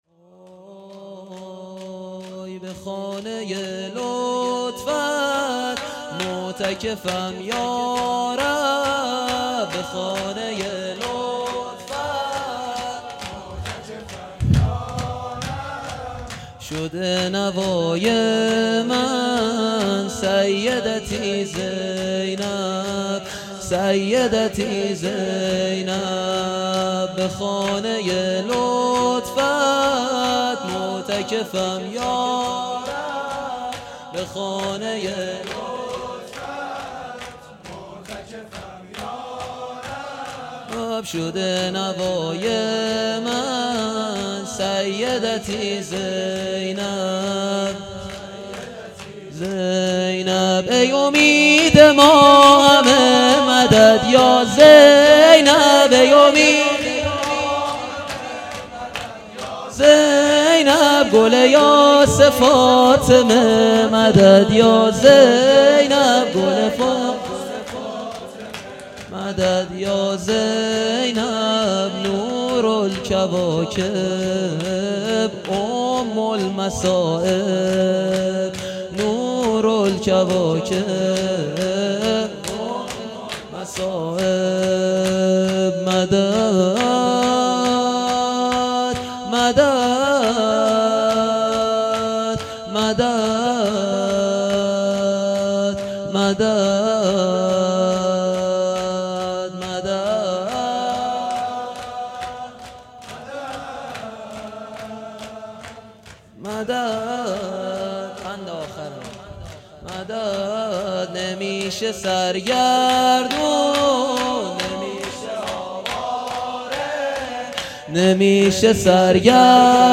به خانه لطفت معتکفم یارب _ شور
شب چهارم محرم الحرام 1441